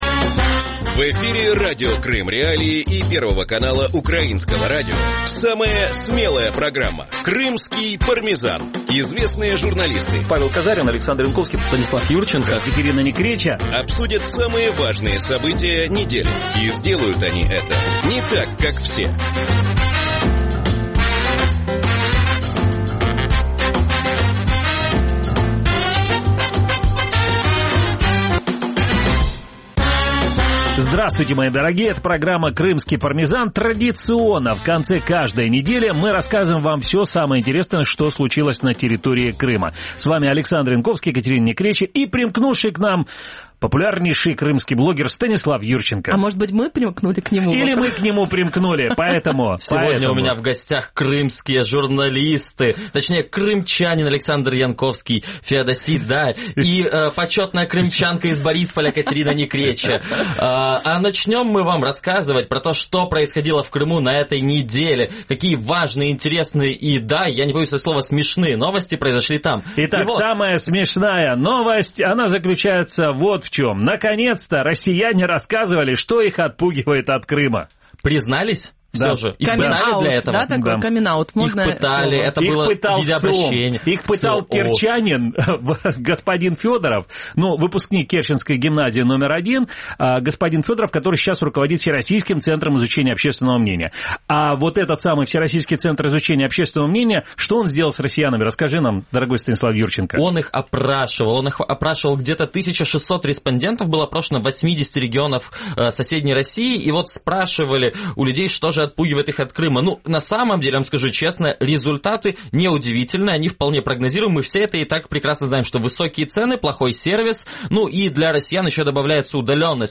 Время эфира в Крыму с 17:00 до 17:45 в эфире Радио Крым.Реалии (105.9 FM), а также на сайте Крым.Реалии. Также программу можно слушать и на волнах украинского Радио НВ.